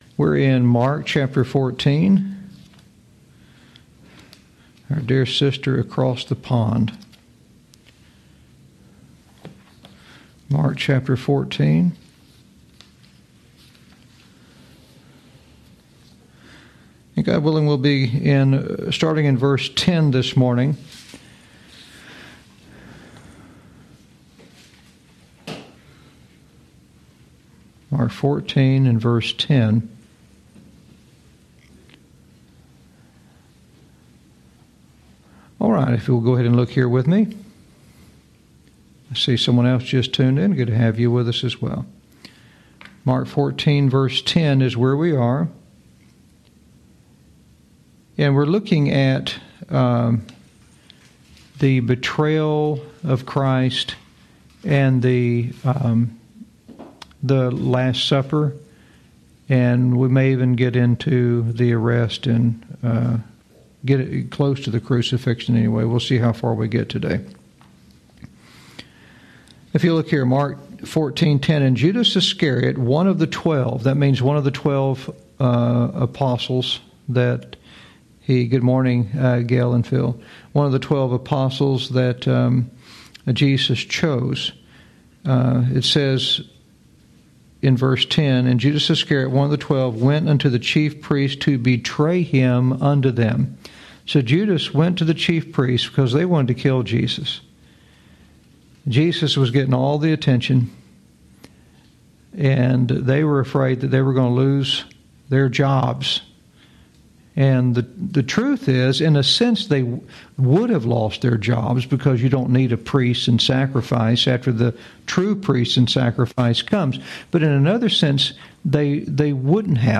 Know Im Saved Bible Teaching - Genesis To Jesus Lesson 38 Jun 16 2024 | 00:42:01 1x 00:00 / 00:42:01 Subscribe Share Apple Podcasts Spotify Overcast RSS Feed Share Link Embed